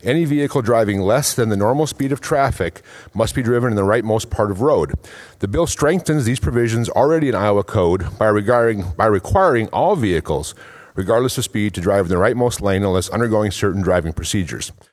The Iowa Senate has passed a bill that would establish a 135-dollar fine for motorists caught lingering too long in the left hand lane. Senator Mike Klemish (KLEHM-ish) of Spillville says under current Iowa law, drivers are to mostly stay in the right hand lane unless they’re passing another vehicle.